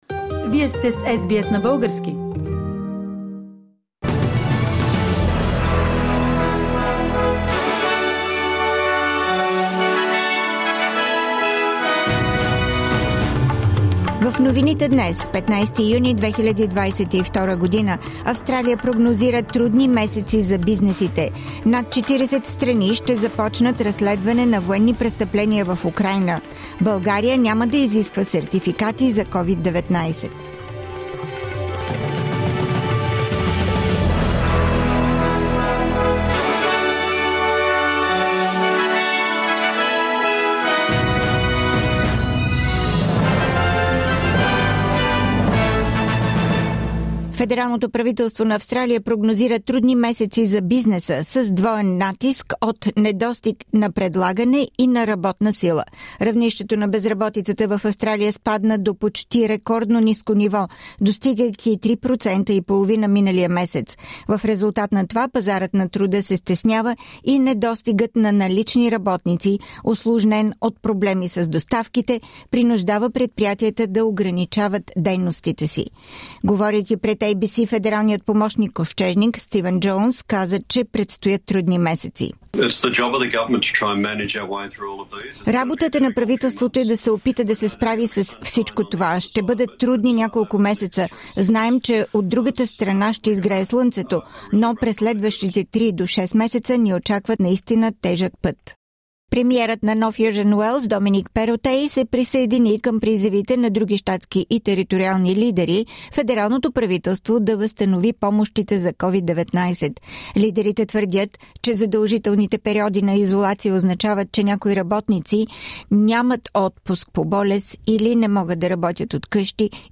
Седмичен преглед на новините.